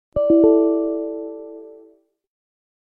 Category: SFX Ringtones